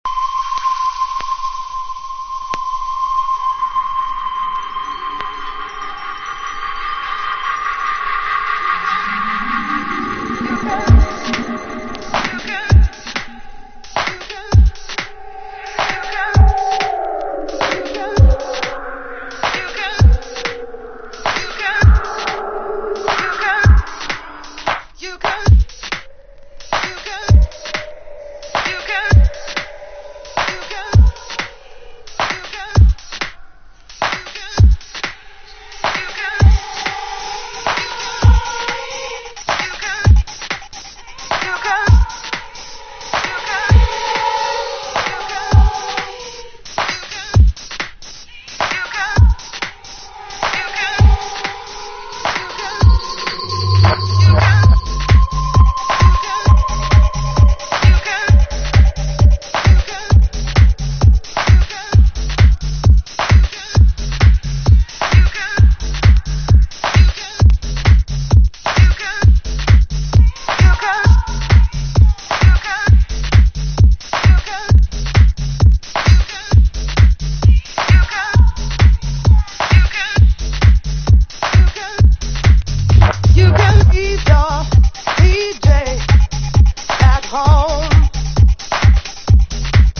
北欧らしい冷えた空気感を持ったダビー・トラック。